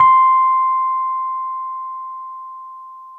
RHODES CL0IL.wav